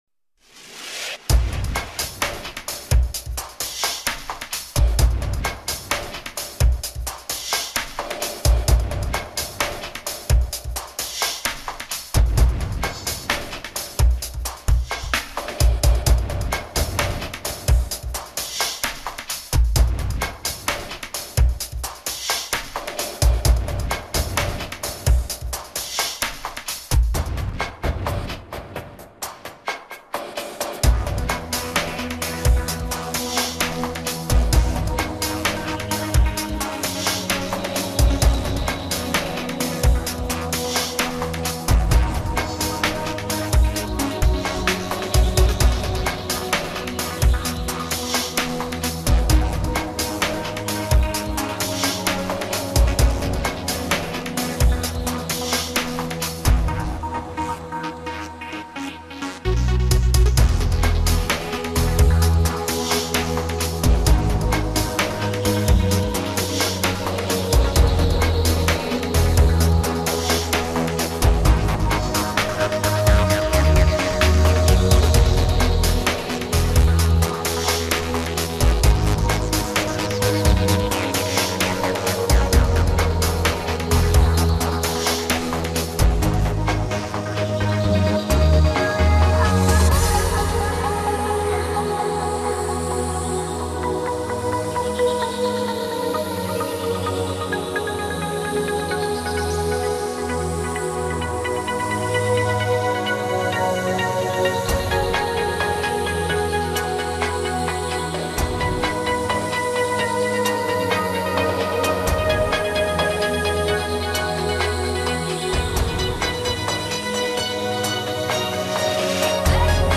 Chill Mix